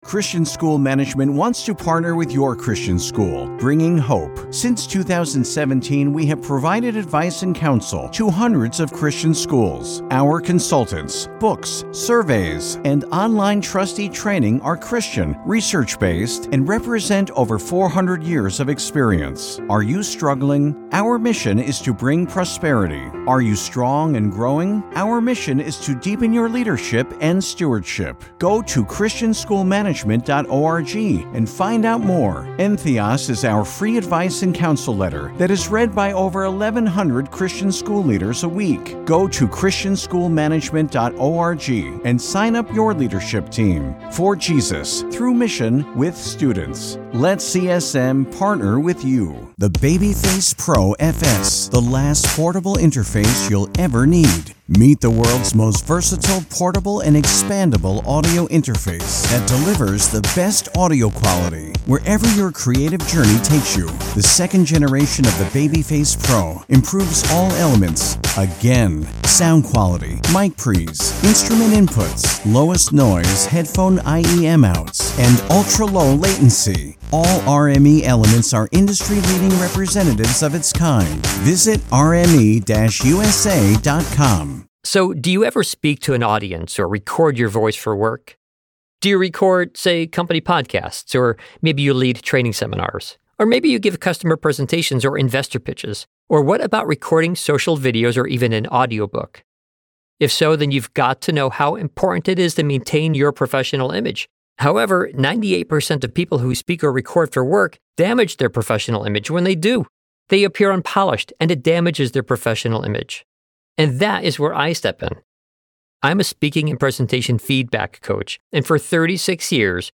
Miracles_of_Jesus_Interview.mp3